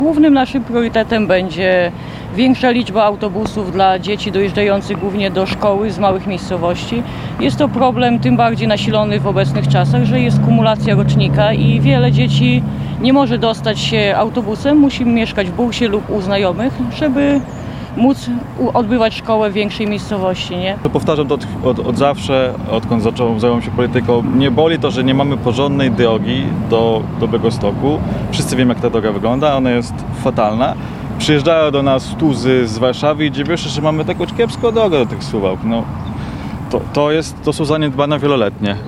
Więcej połączeń autobusowych dla uczniów i remontów dróg chcą kandydaci Sojuszu Lewicy Demokratycznej do sejmu z Suwalszczyzny. Swoje wyborcze postulaty w tym zakresie przedstawili w czwartek (26.09) na dworcu autobusowym w Suwałkach